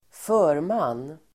Uttal: [²f'ö:rman:]